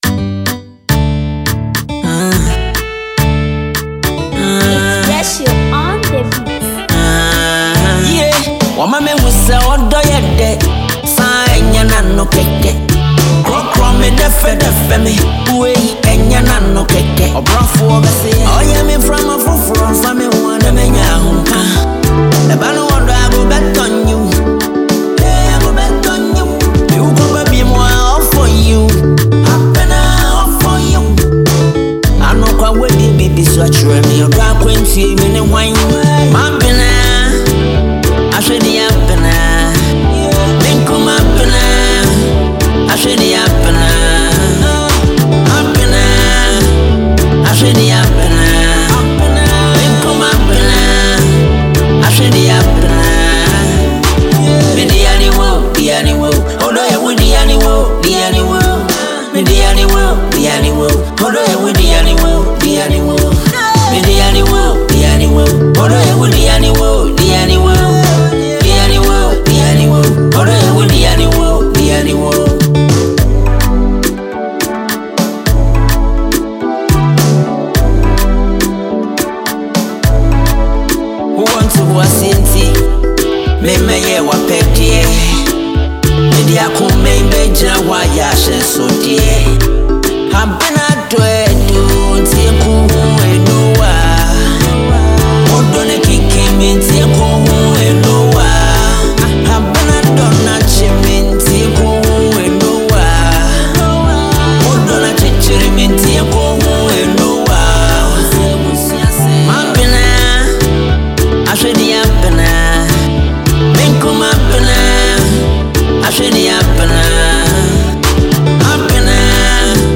New song from Ghanaian singer